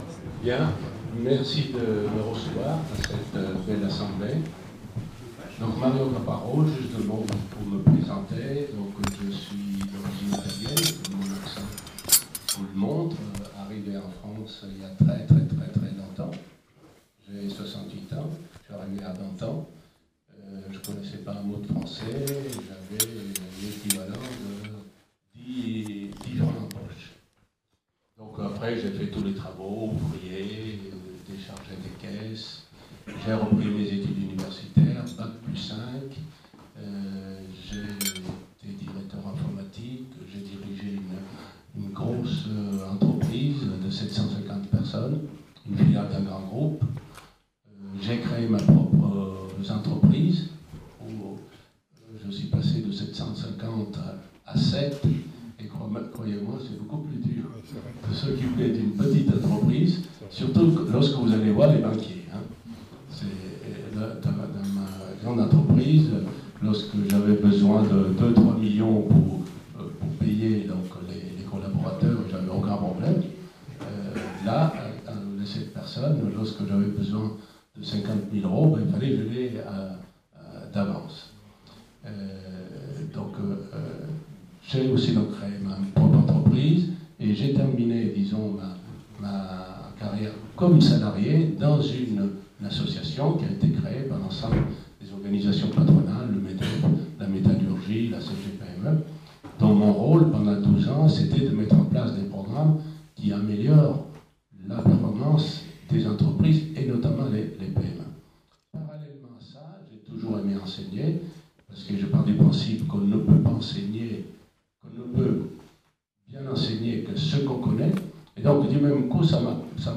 Conférence.mp3